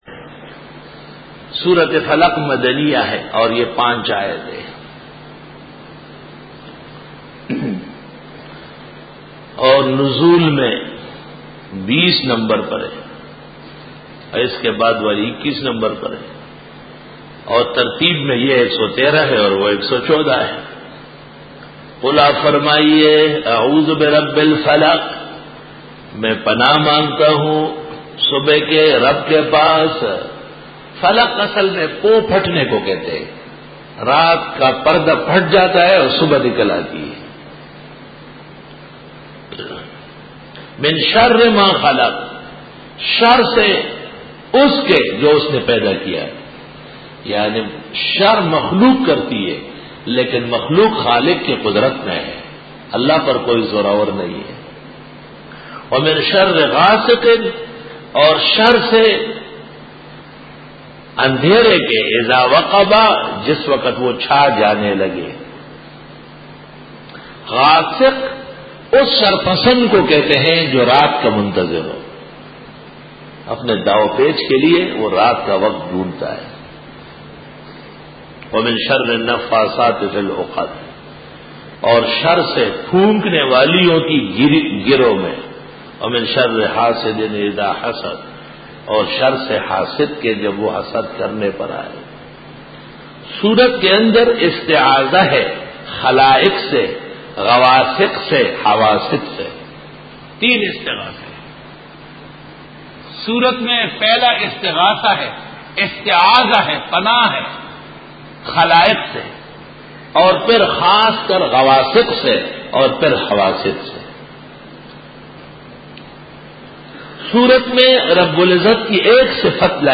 Bayan